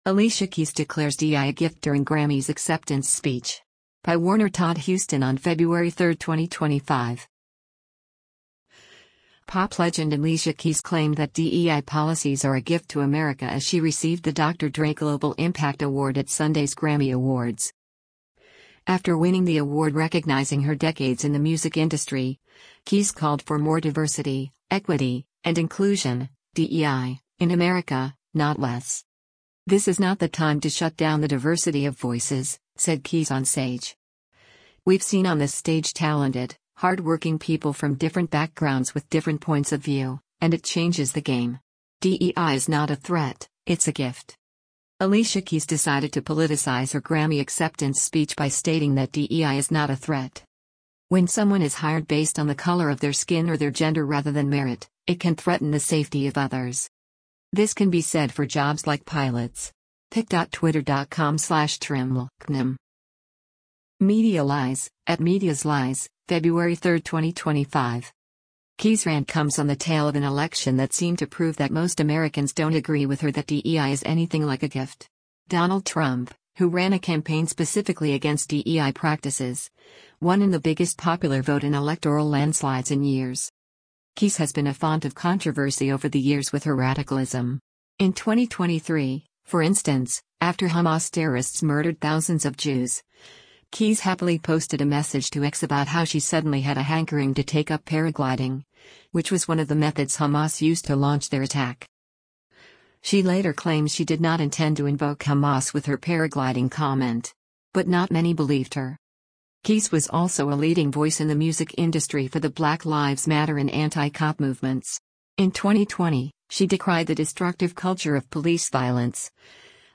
Alicia Keys Declares ‘DEI a Gift’ During Grammys Acceptance Speech
Pop legend Alicia Keys claimed that DEI policies are a “gift” to America as she received the Dr. Dre Global Impact Award at Sunday’s Grammy Awards.